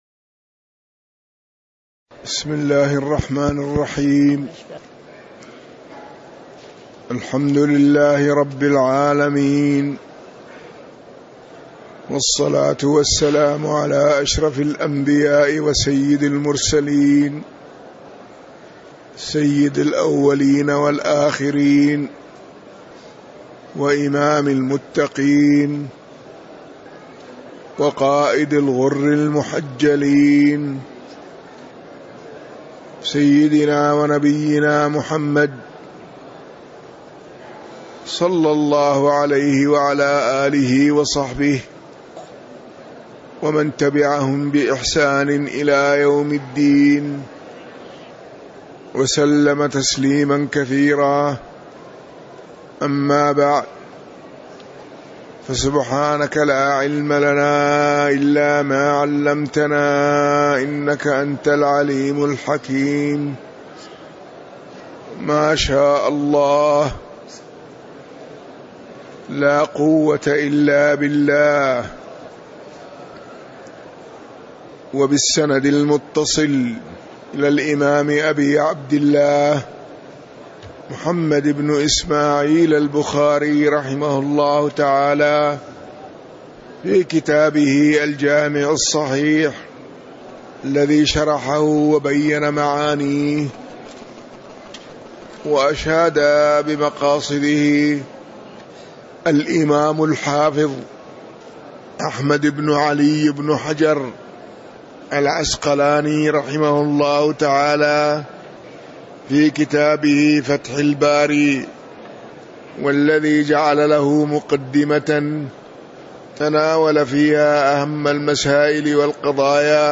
تاريخ النشر ٩ ربيع الثاني ١٤٤٠ هـ المكان: المسجد النبوي الشيخ